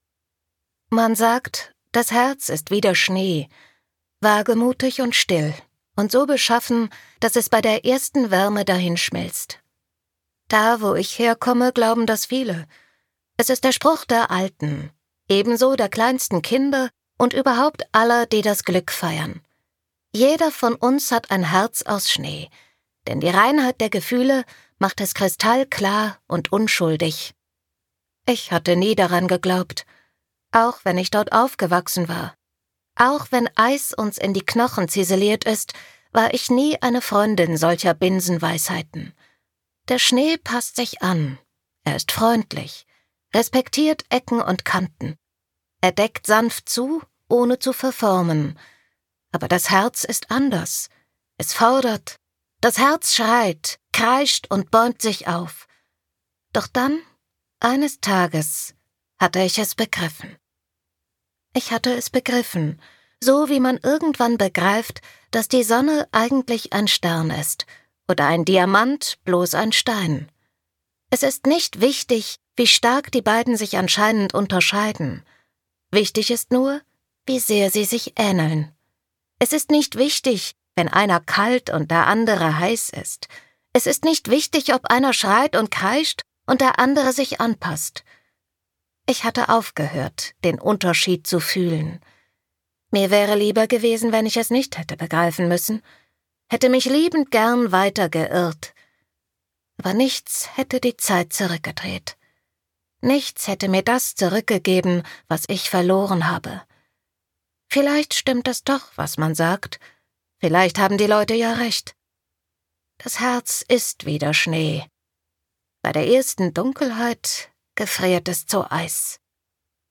Gekürzt Autorisierte, d.h. von Autor:innen und / oder Verlagen freigegebene, bearbeitete Fassung.
Switch Studio, Berlin, 2022/ argon verlag